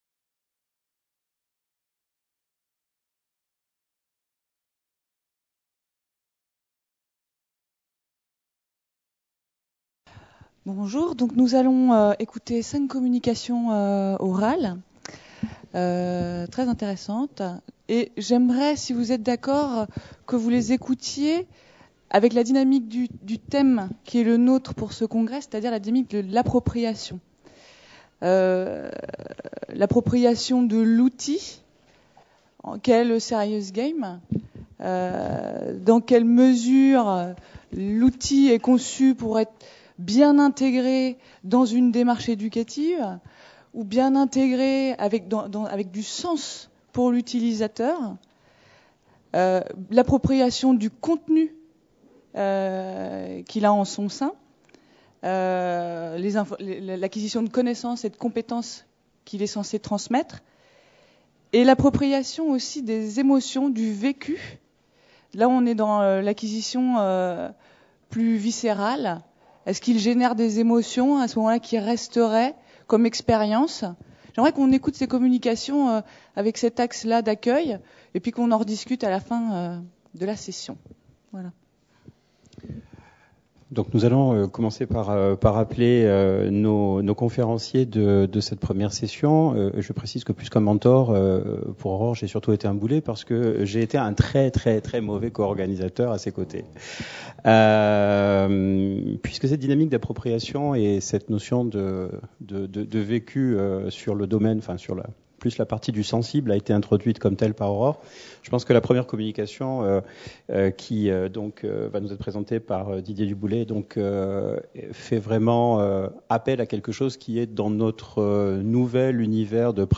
Conférence enregistrée dans le cadre de la 2ème conférence nationale sur l’usage des jeux sérieux dans l’enseignement et l’apprentissage en médecine et en santé (SeGaMed 2013) à Nice les 12 et 13 septembre 2013.